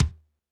Drums_K4(54).wav